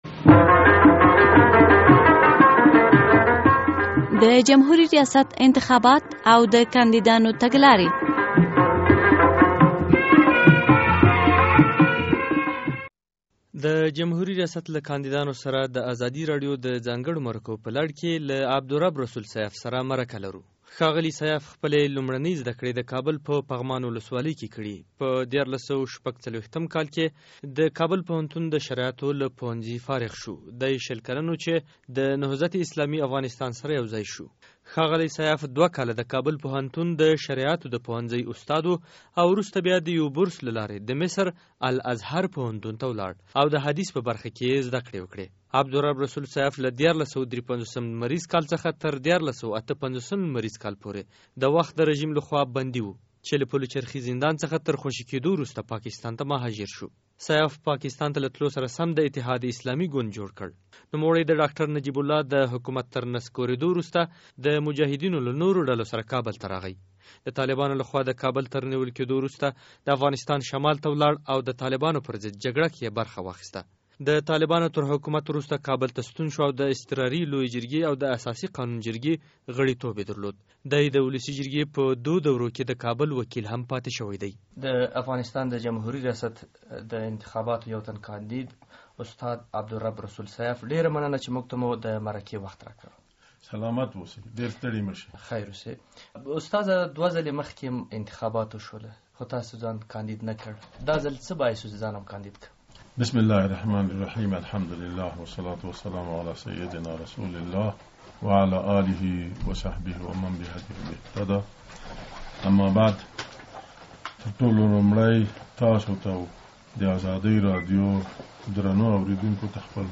له عبدالرب رسول سیاف سره مرکه